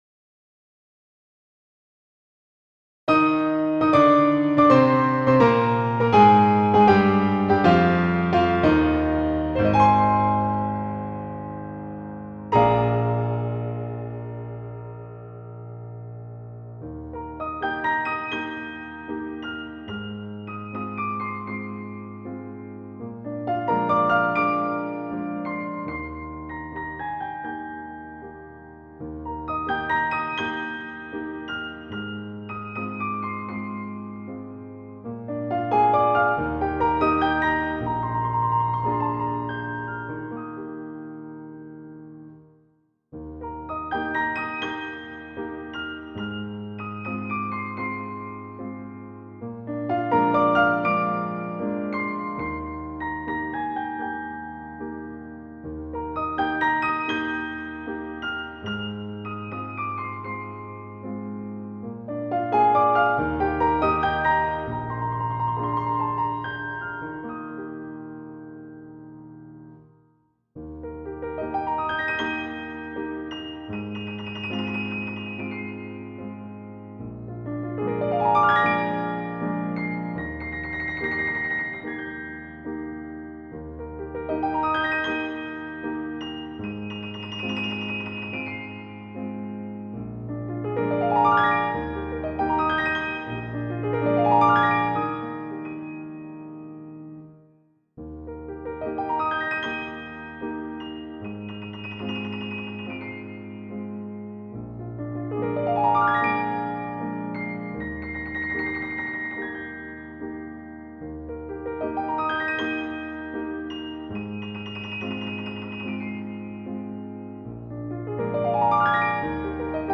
L’opera prende vita su La Prière d’une Vierge, romantica composizione per pianoforte scritta da Tekla Badarzewska-Baranowska attorno al 1856 e pubblicata in Italia da F. Lucca di Milano.